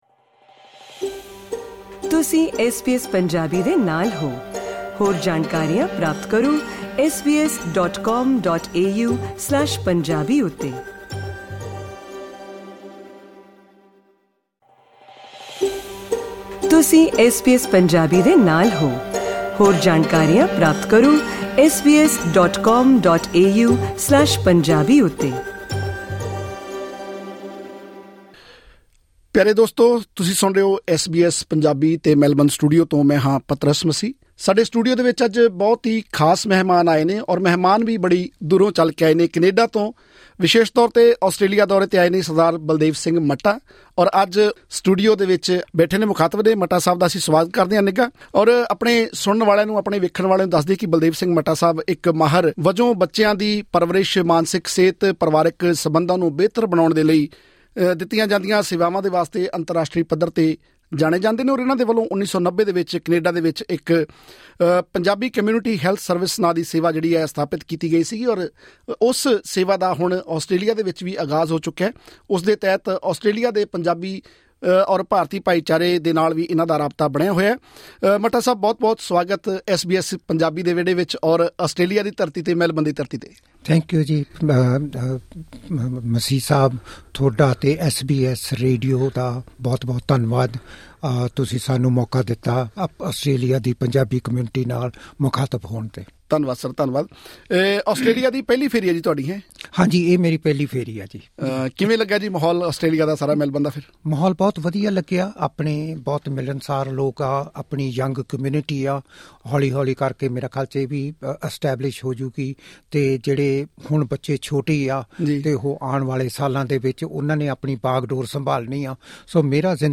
ਐਸ ਬੀ ਐਸ ਦੇ ਮੈਲਬਰਨ ਸਟੂਡੀਓ ਵਿਖੇ ਗੱਲਬਾਤ ਦੌਰਾਨ।